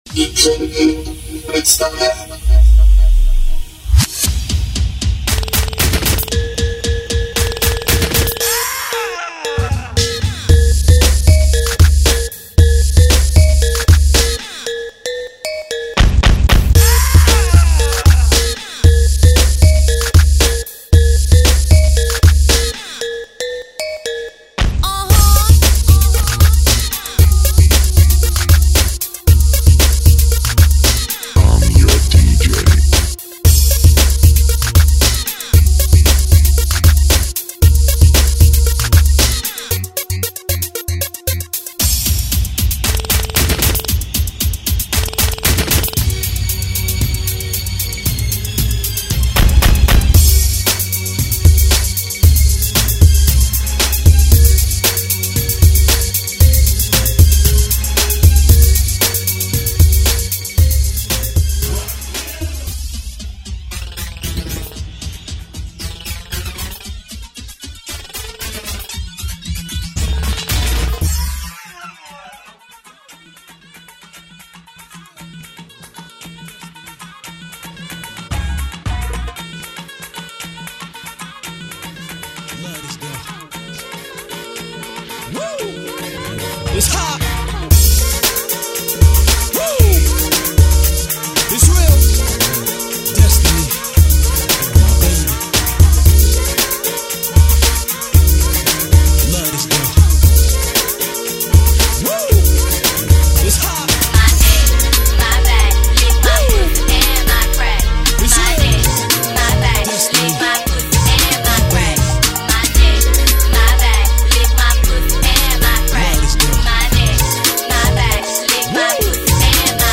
басы-долбят-мягкий-басс
basy_dolbyat_myagkiq_bass.mp3